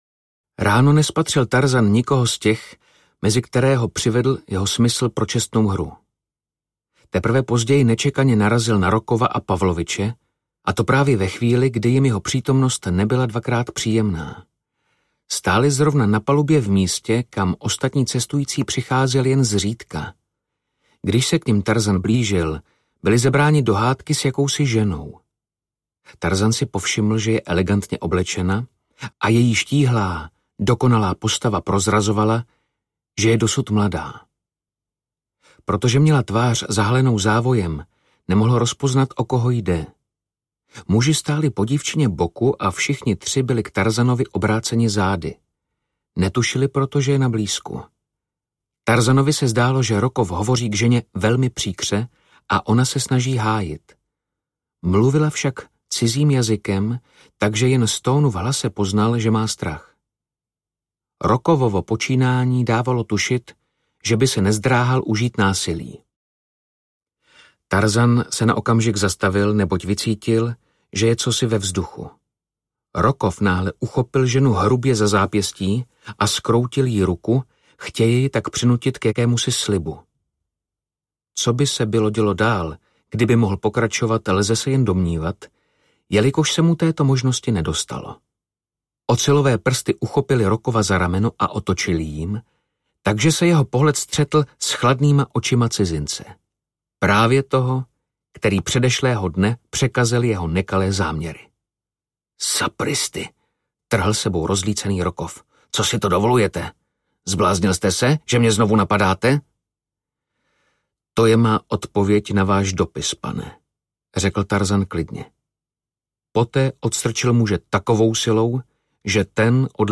Tarzanův návrat audiokniha
Ukázka z knihy
Vyrobilo studio Soundguru.
tarzanuv-navrat-audiokniha